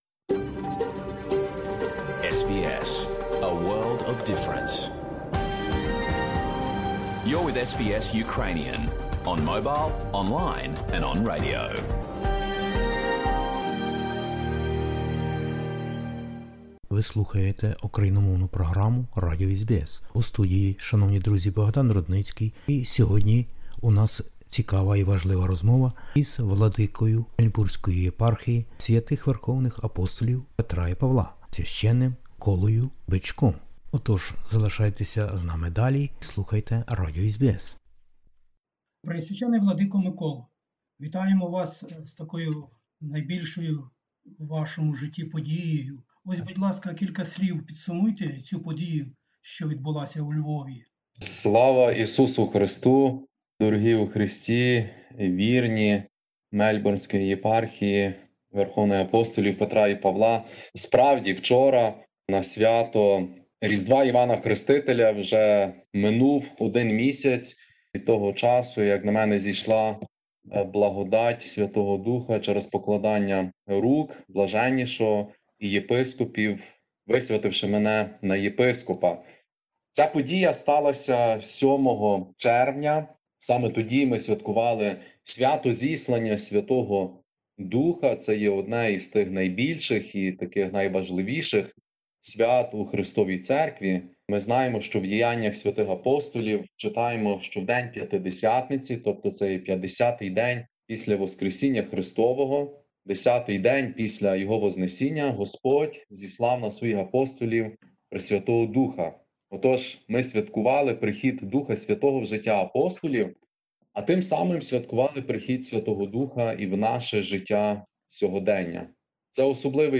розмовляє із Преосв. Владикою Миколою, новим Єпископом УГКЦ. Тут кілька поглядів на минулу величаву й уже історичну подію, зокрема, для нового Владики та Мельбурнської єпархії Свв. Верховних Апостолів Петра і Павла.